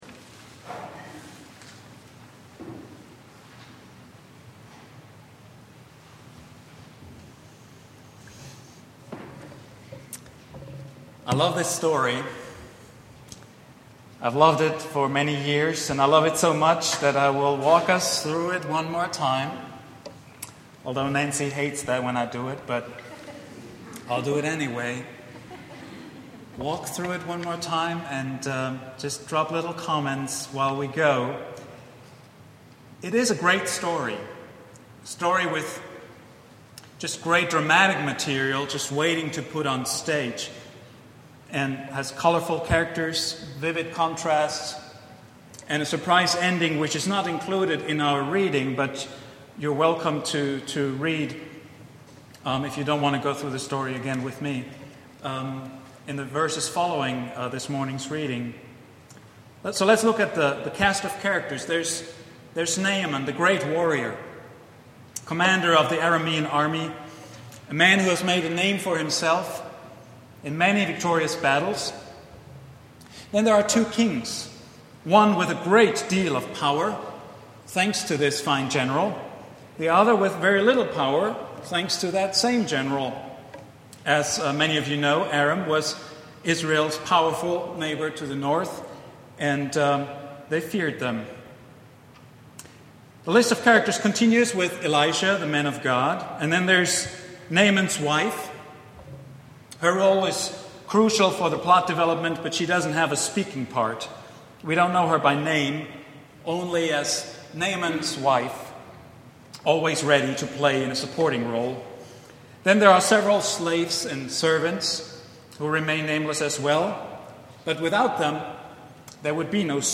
sermon
preached at Vine Street Christian Church on July 4, 2010.